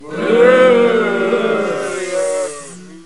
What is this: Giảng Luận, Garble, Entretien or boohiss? boohiss